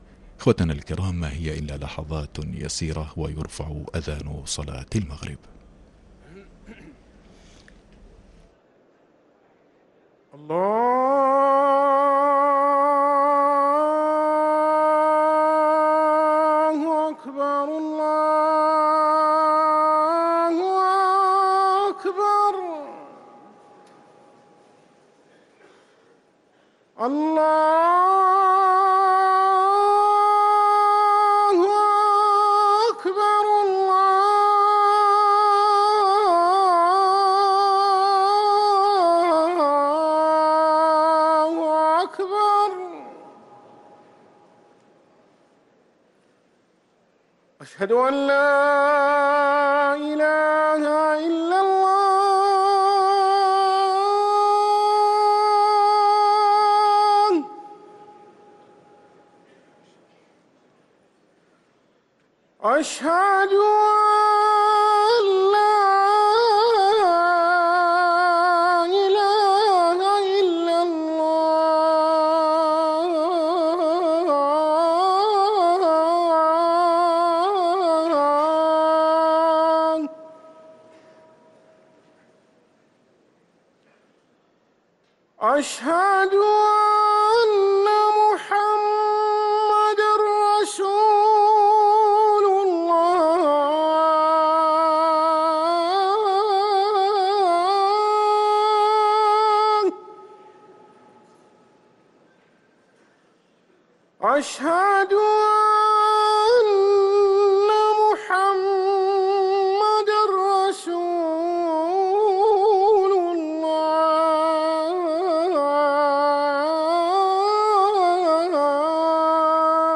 أذان المغرب